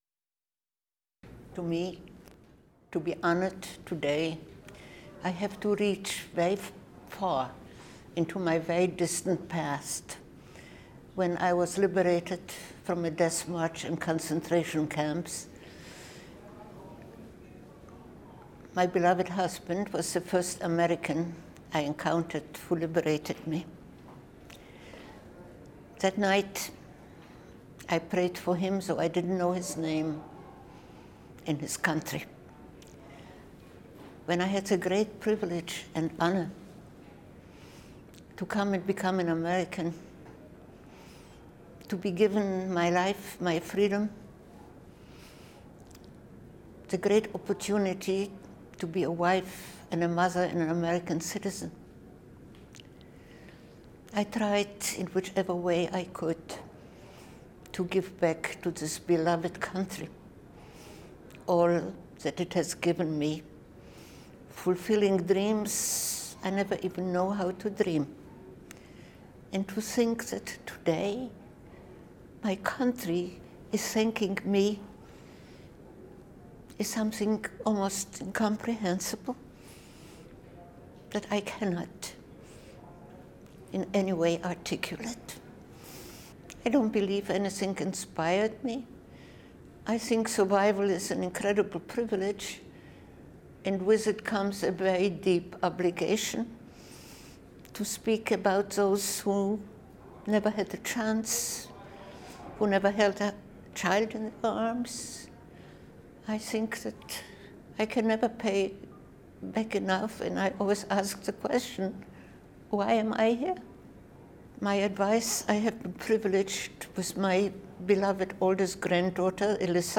Her remarks are made on the occasion of her receiving the 2010 Presidential Medal of Freedom.